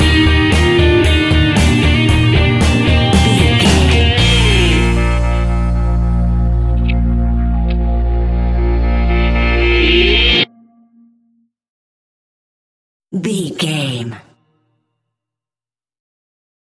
Uplifting
Ionian/Major
B♭
pop rock
indie pop
fun
energetic
cheesy
instrumentals
guitars
bass
drums
piano
organ